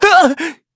Evan-Vox_Damage_kr_04.wav